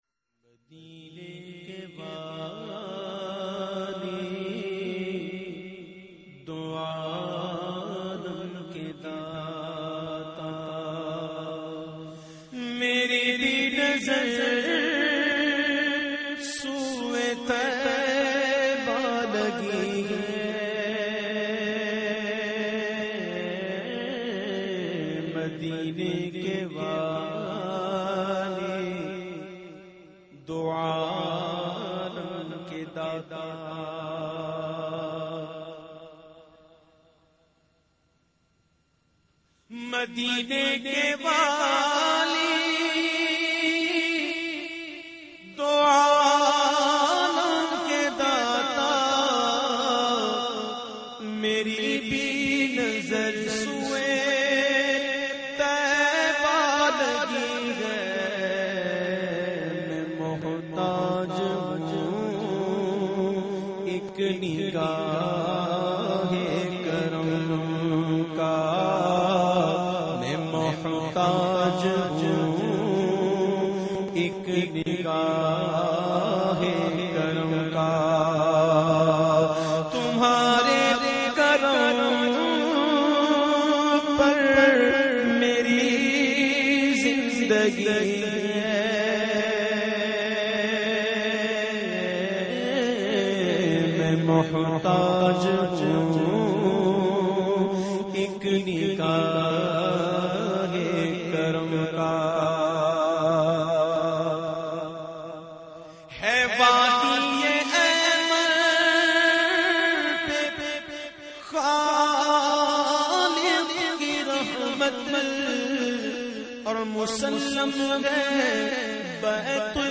The Naat Sharif Madine Ke Wali recited by famous Naat Khawan of Pakistan Owais Raza Qadri.